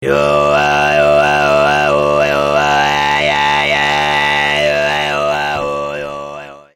Pick up your voice, produce a long stretched vowel A or UM.
Do you know what VOICE OVERTONES are? LISTEN to the following MP3- audio-sample.
OVERTON.mp3